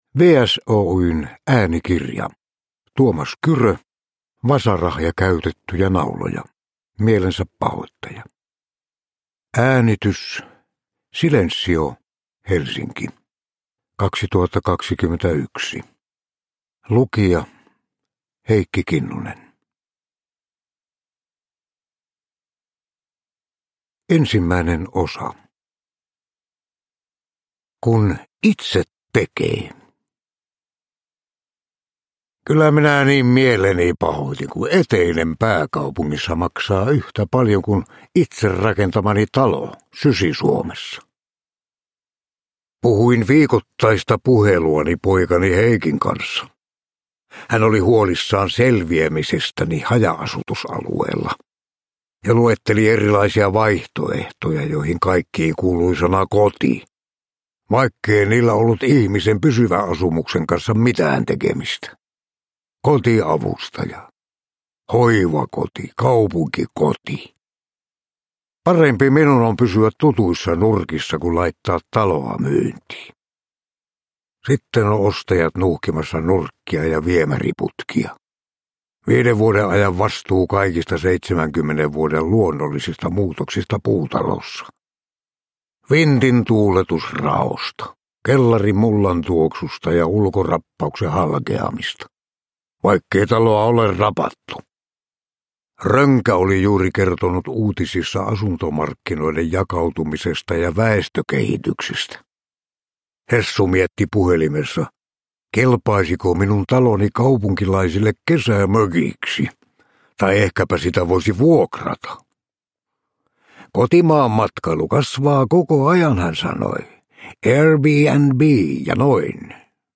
Vasara ja käytettyjä nauloja, Mielensäpahoittaja – Ljudbok – Laddas ner
Uppläsare: Heikki Kinnunen